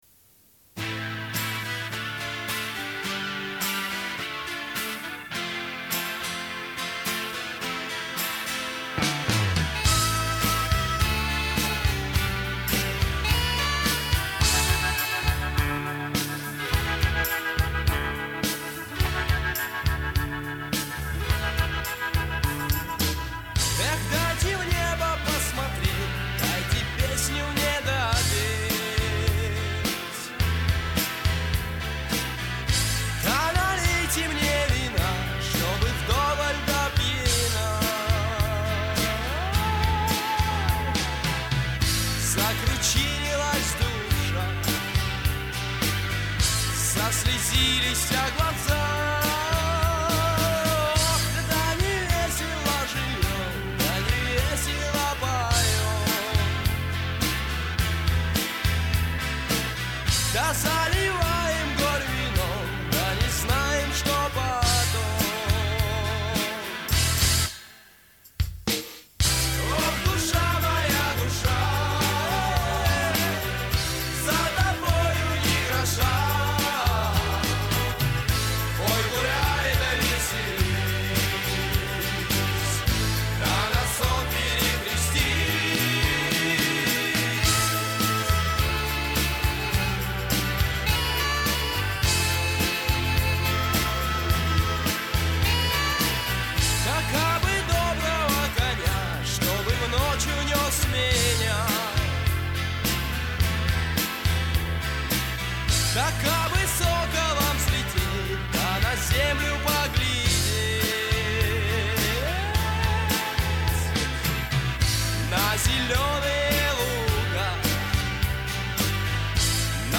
вокал
гитара
клавишные
бас
барабаны, бэк-вокал